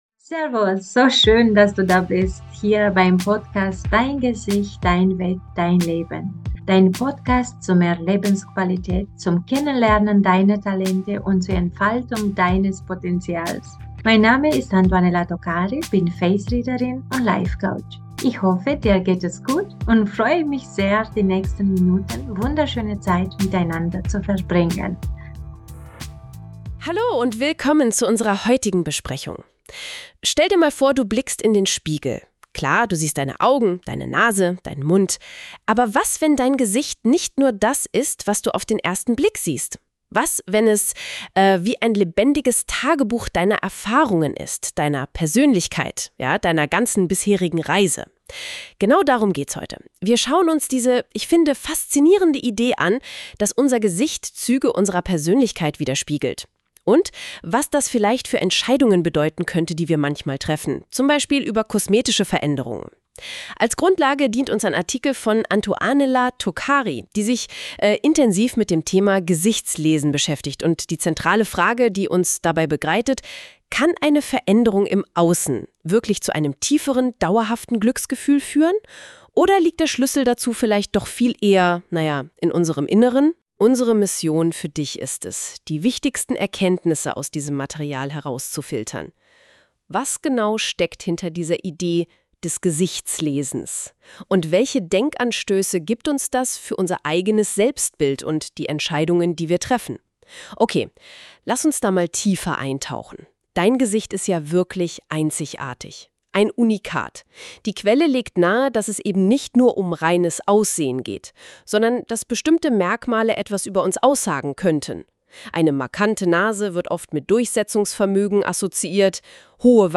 Dieser Podcast wurde mit Unterstützung von KI auf Basis meiner Website-Inhalte erstellt. Er verbindet Business-Coaching mit den Erkenntnissen des Face Readings und zeigt, wie Dein äußeres Erscheinungsbild Deine innere Haltung widerspiegelt.